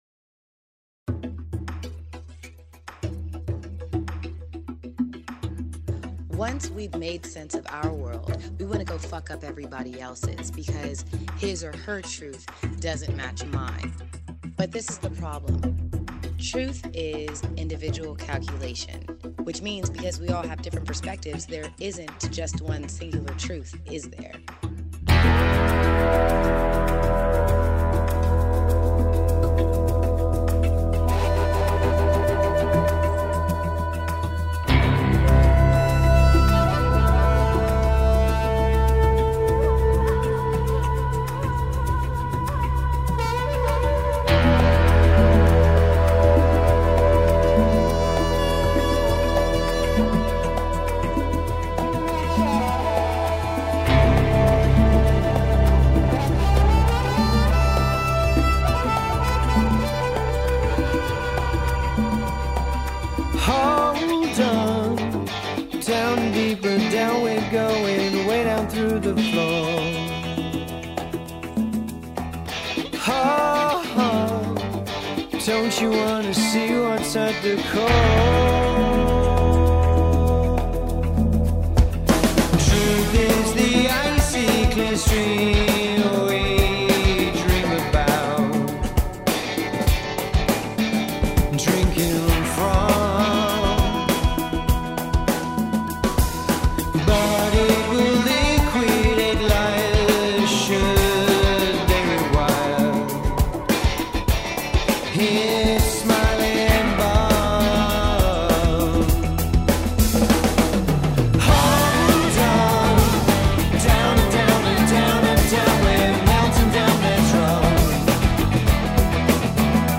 Progressive Pop, Progressive Rock, Art Rock, Pop Rock